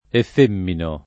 vai all'elenco alfabetico delle voci ingrandisci il carattere 100% rimpicciolisci il carattere stampa invia tramite posta elettronica codividi su Facebook effeminare v.; effemino [ eff % mino ] — anche effemminare : effemmino [ eff % mmino ] — non efeminare